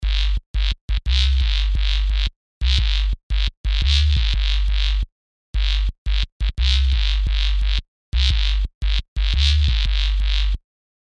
暴躁的老人包 " 暴躁的老人3
标签： 老了 老男人 脾气暴躁
声道立体声